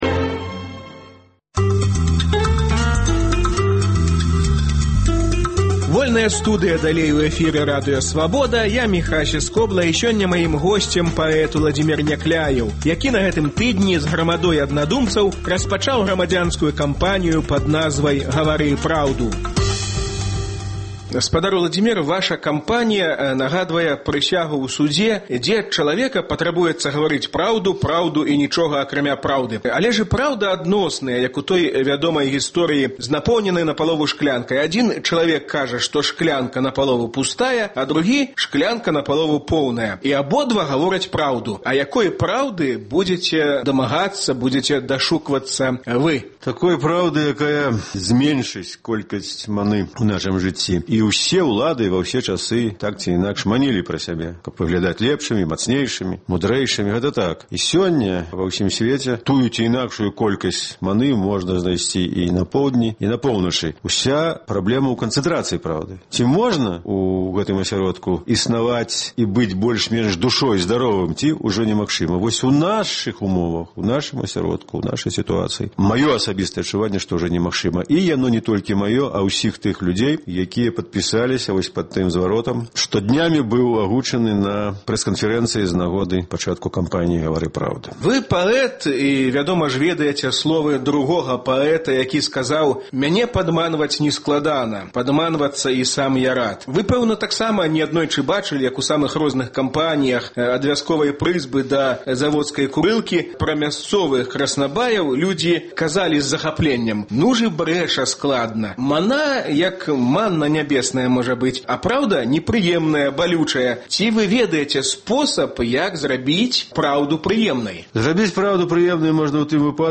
Гутарка з Уладзімерам Някляевым наконт грамадзянскай кампаніі “Гавары праўду!”